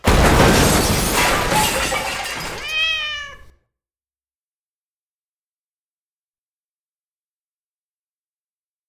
large crash with cat.aiff
Category 🐾 Animals
big cat comedic comic crash enormous huge smash sound effect free sound royalty free Animals